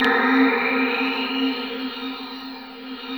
21PAD 02  -L.wav